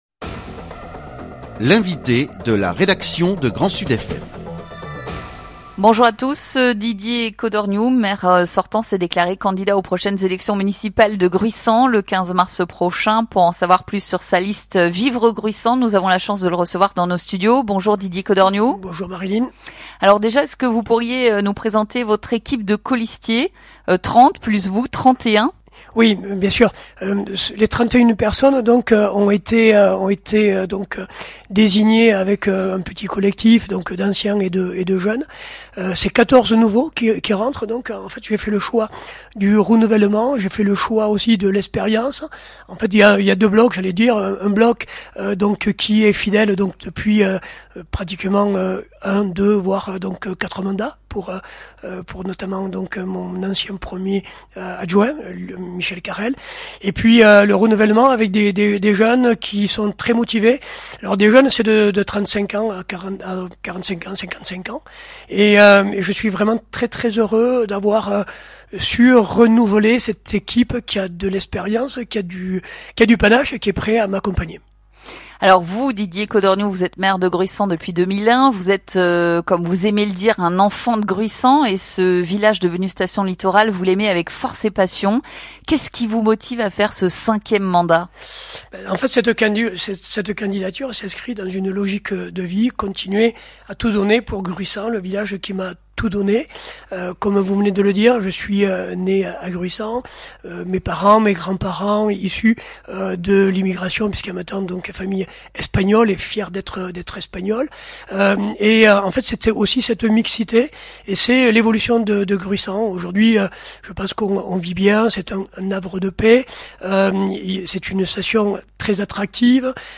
Didier Codorniou, Maire sortant et candidat de la liste « Vivre Gruissan » présente son programme et nous explique ce qui l’incite à se représenter aux prochaines municipales de mars 2026.